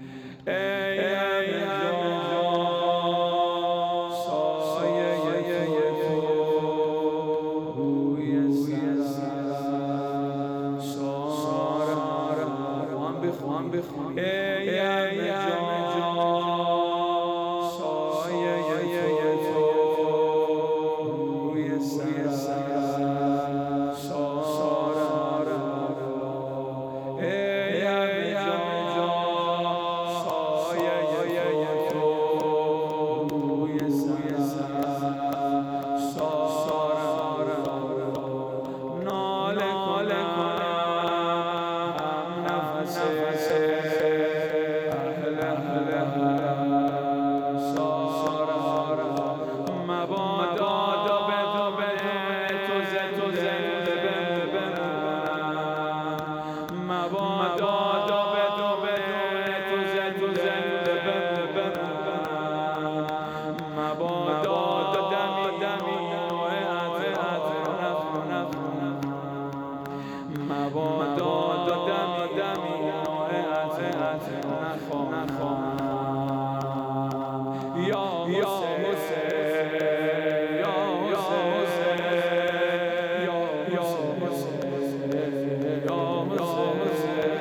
زمینه ، ای همه جا ، شب دوم محرم ۱۴۰۴ ، فضای باز حسینیه معظم ریحانة الحسین سلام الله علیها،